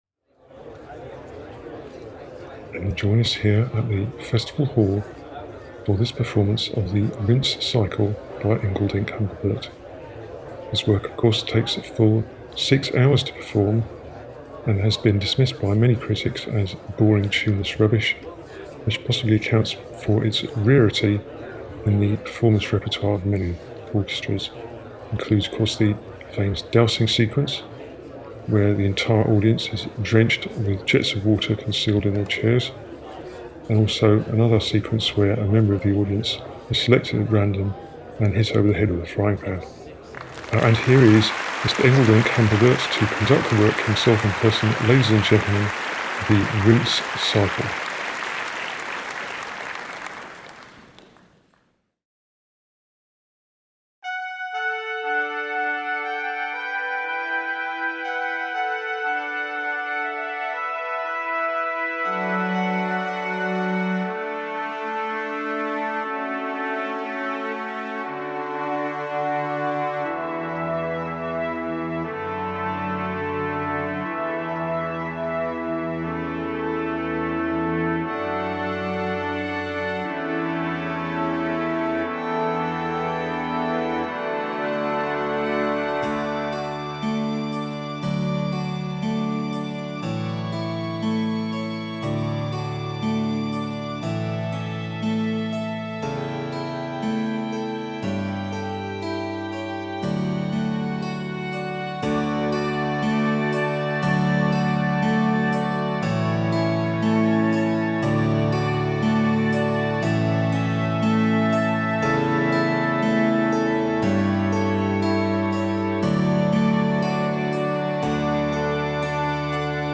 Interlude (instrumental) - Orchestrated: 25th June - 5th July 2012.
It's another attempt to start a CD as if it were a piece of classical music.